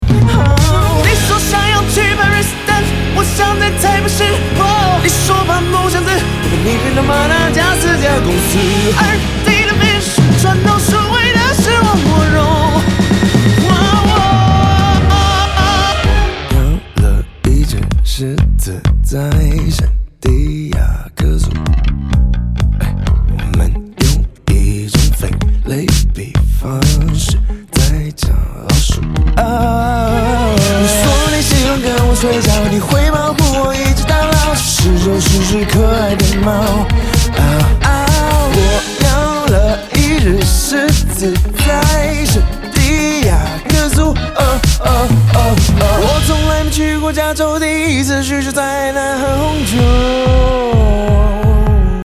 Disco, Dance-pop, Funk
authentic taiwanese disco band